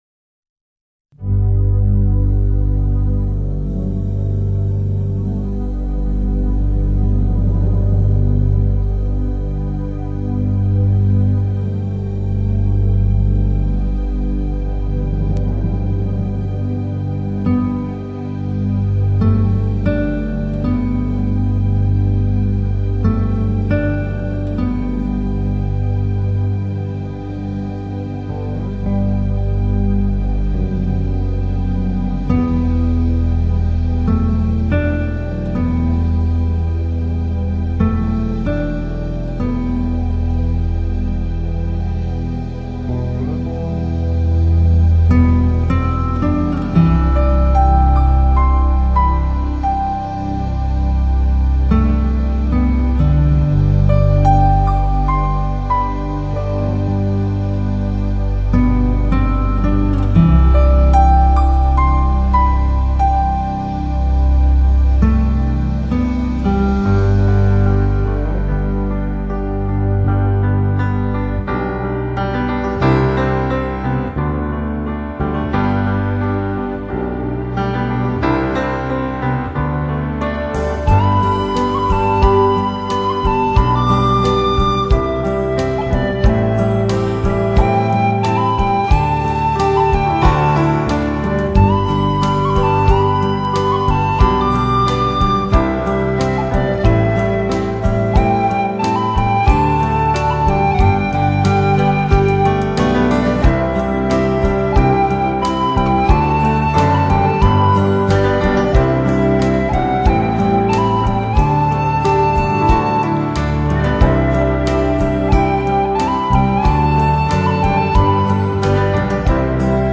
钢琴音乐